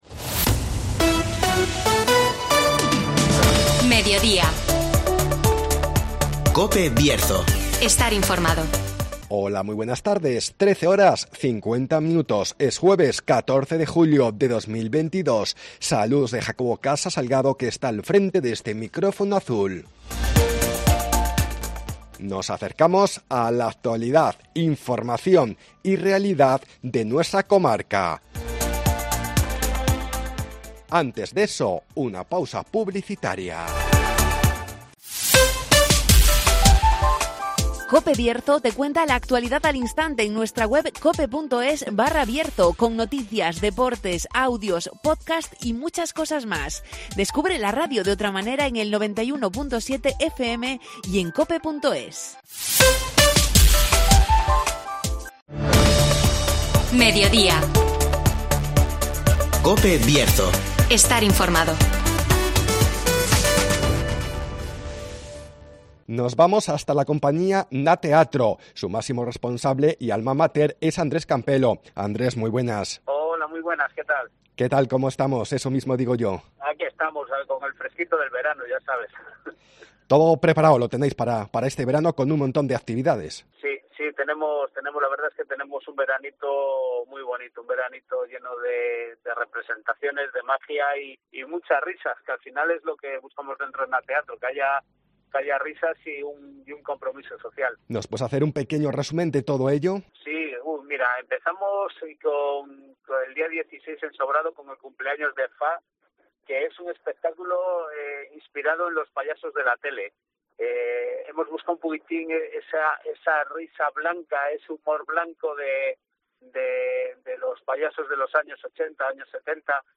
ACTUALIDAD